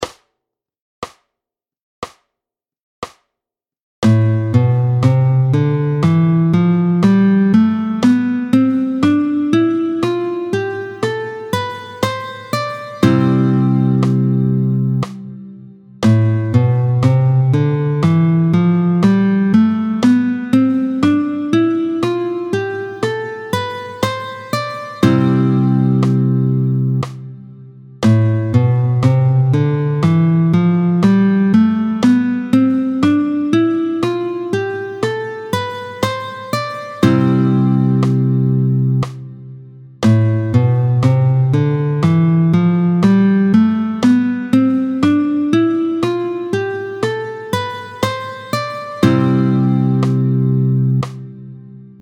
26-12 Doigté 6, Do majeur, tempo 60